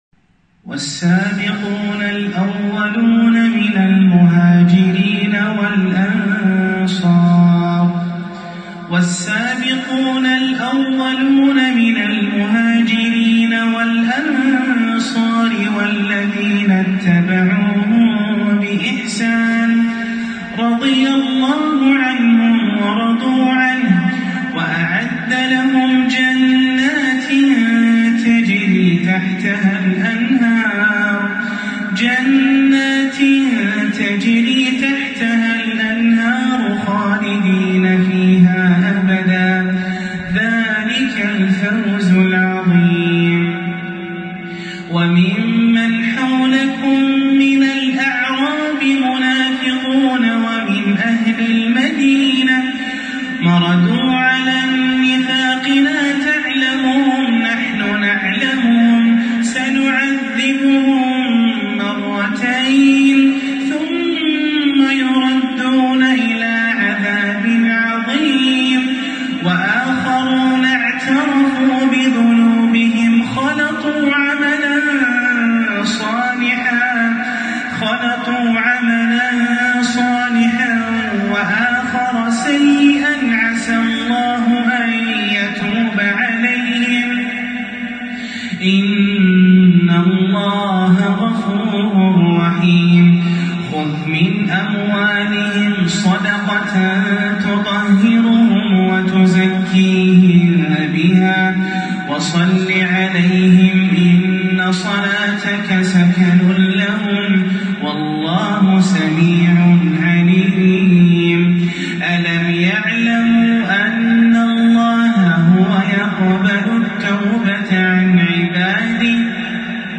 تلاوة هادئة خاشعة من سورة التوبة للقارئ